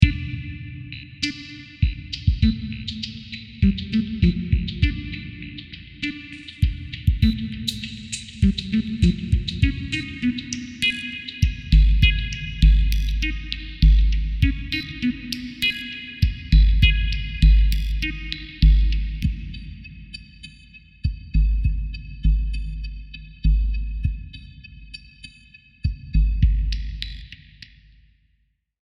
Bucle de Intelligent dance music (IDM)
Música electrónica Dance pieza percusión melodía música repetitivo rítmico sintetizador